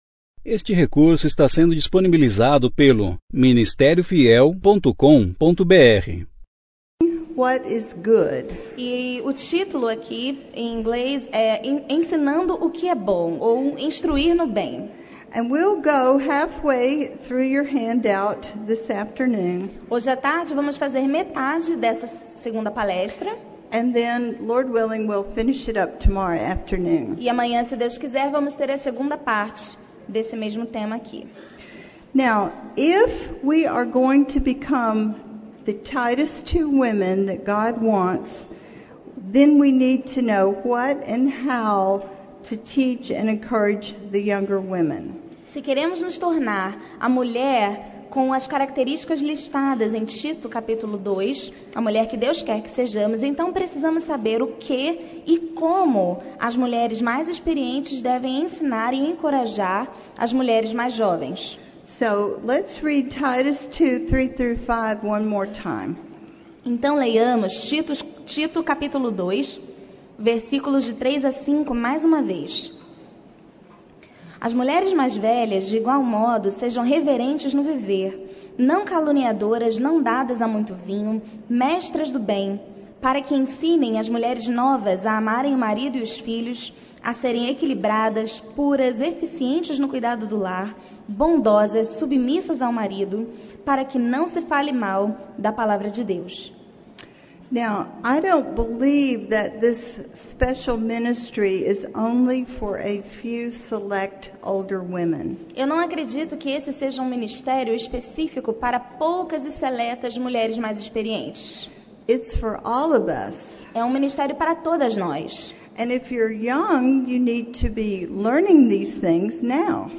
Conferência: 28ª Conferência Fiel para Pastores e Líderes Tema: Alicerces da Fé Cristã – O que me torna um cristão?